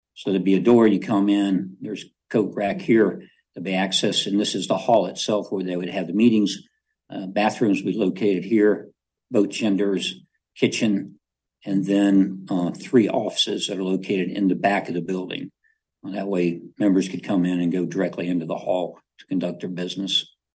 County Administrator Bud Norman described how the renovated building would look like when he made a power point presentation during Tuesday’s Board of Commissioners meeting.